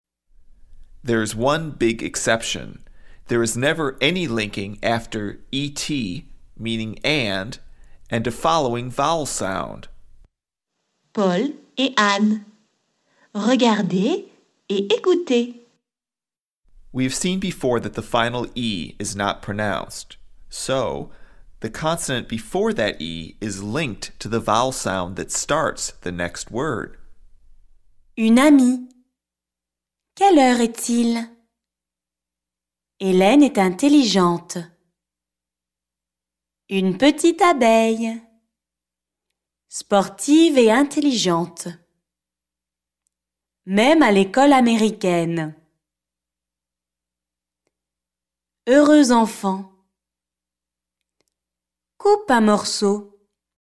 There is one big exception: There is never any linking after “et” and a following vowel sound.